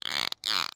mob / dolphin / idle6.ogg